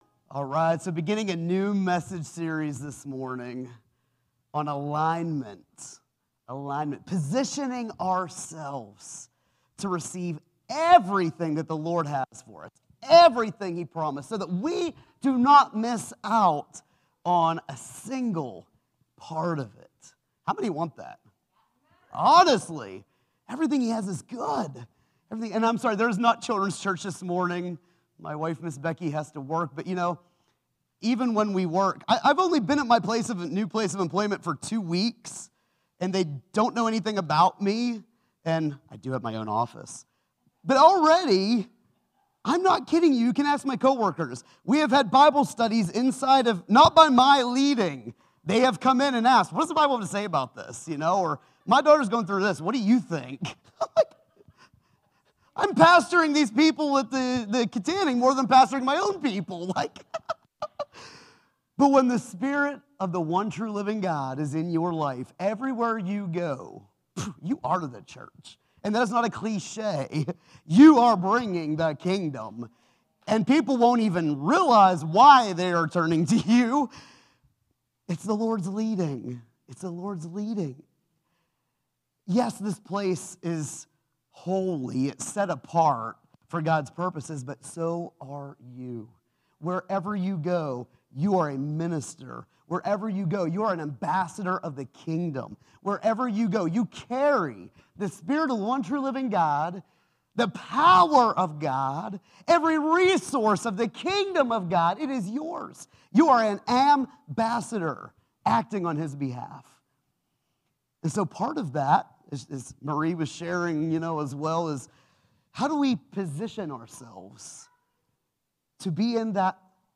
This morning, we’re beginning a new message series entitled, “Align.”